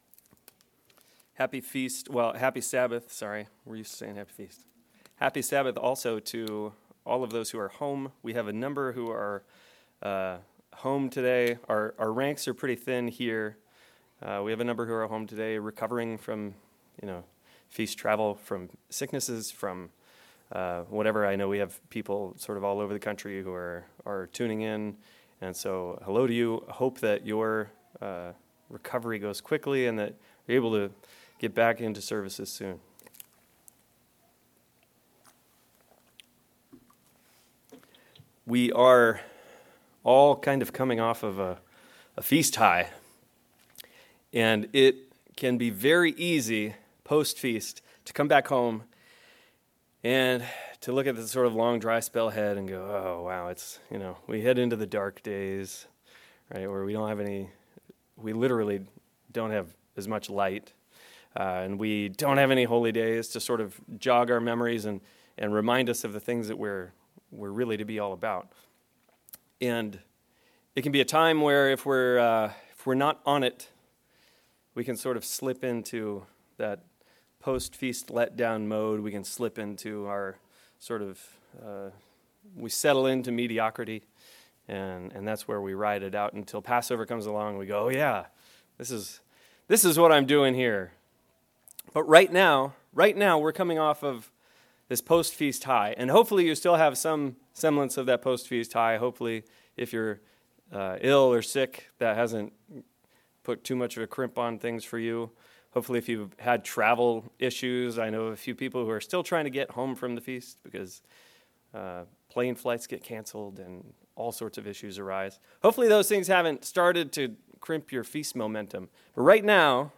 Sermons
Given in Cincinnati East, OH